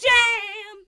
01 RSS-VOX.wav